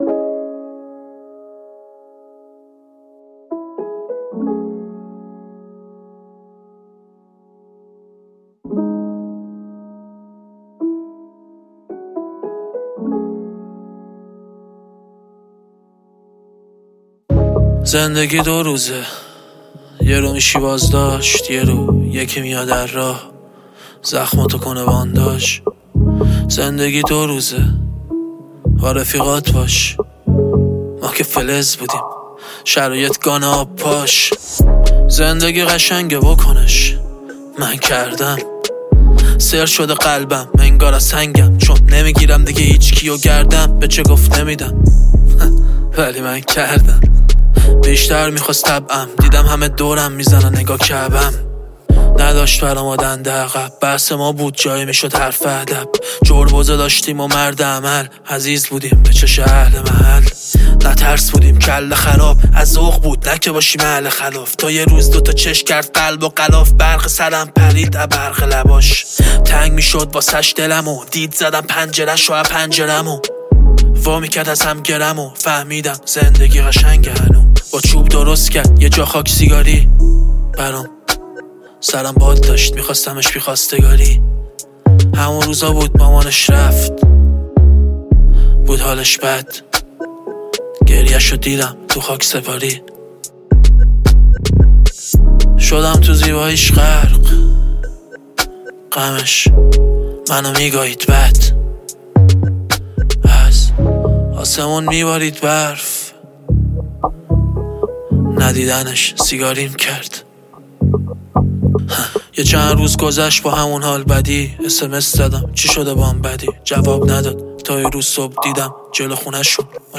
یک قطعه احساسی و روایت‌گر است